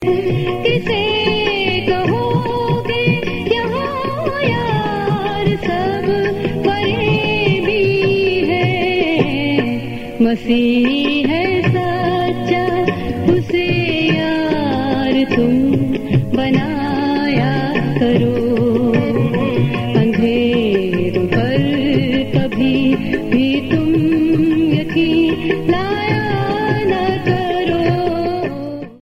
Christian & Gospel RingTones